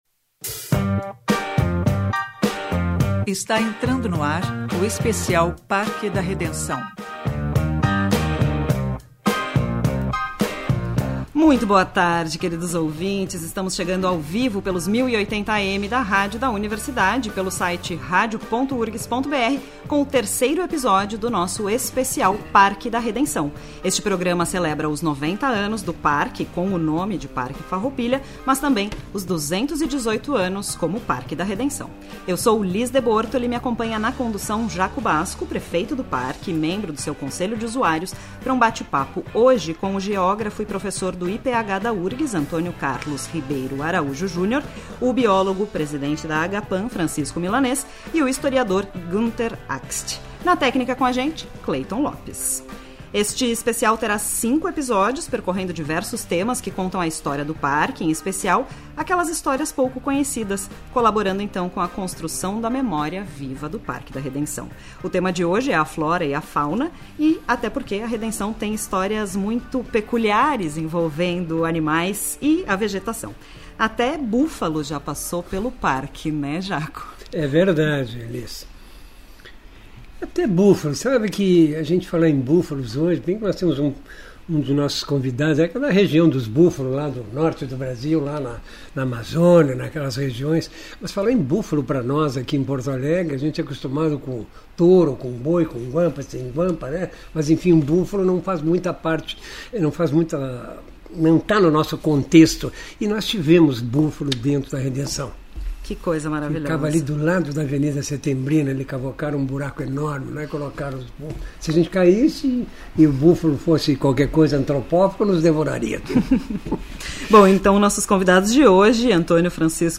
ESPECIAL REDENÇÃO AO-VIVO 05-12-25.mp3